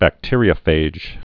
(băk-tîrē-ə-fāj)